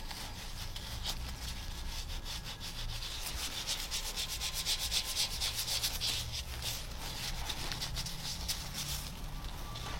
Kling-Audio-Eval / Human sounds /Hands /audio /14956.wav